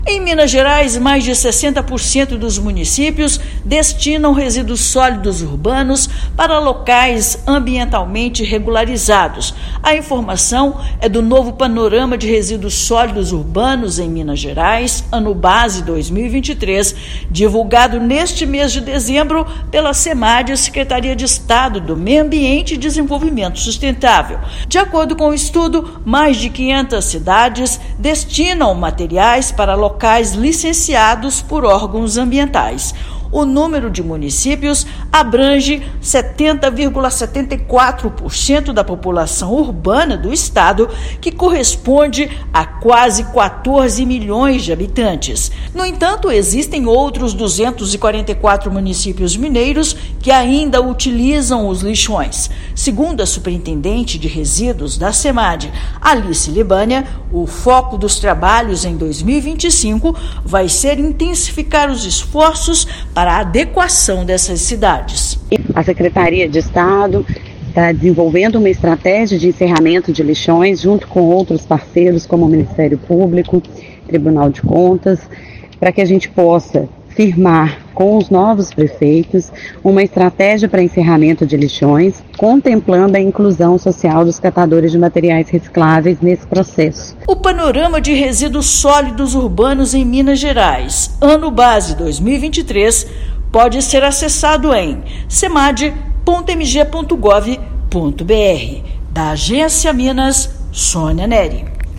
Estudo da Secretaria de Estado de Meio ambiente de Desenvolvimento Sustentável é referente ao final do ano de 2023. Ouça matéria de rádio.